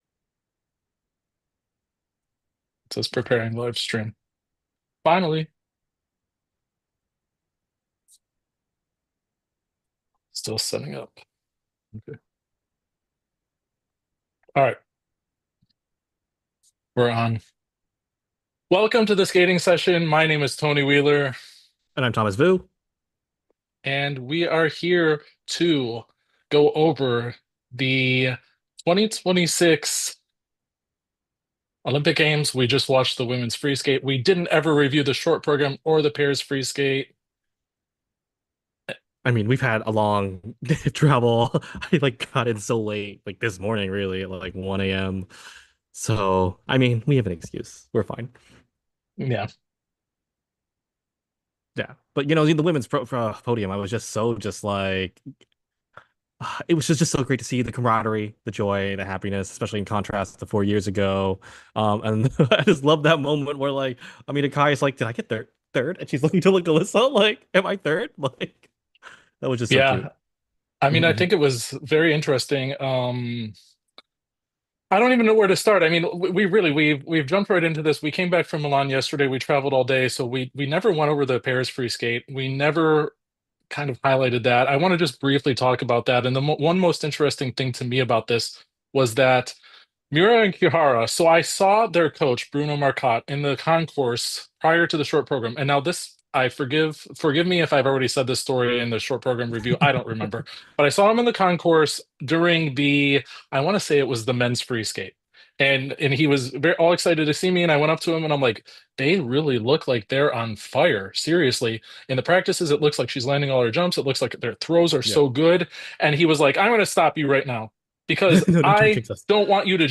We streamed live and had some technical hiccups in the beginning. We also couldn't figure out how to end the live recording.